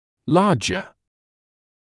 [‘lɑːʤə][‘лаːджэ]больший, более крупный (ср.ст. от large)